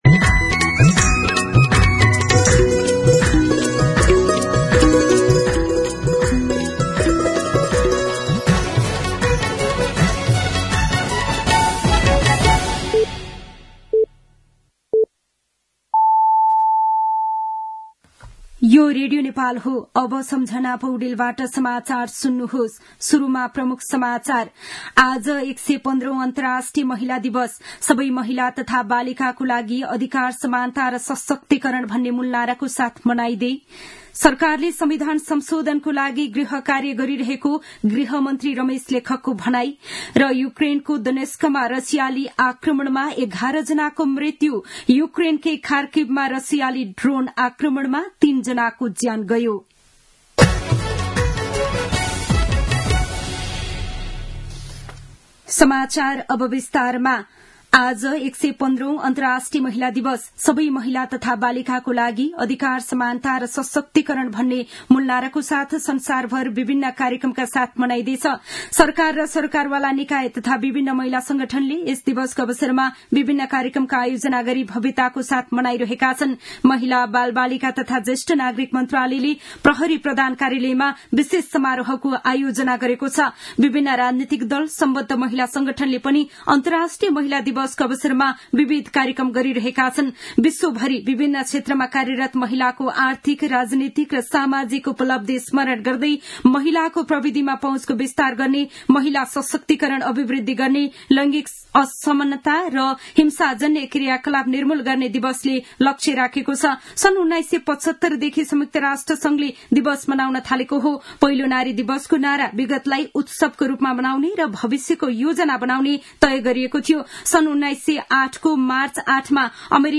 दिउँसो ३ बजेको नेपाली समाचार : २५ फागुन , २०८१
3-pm-Nepali-News.mp3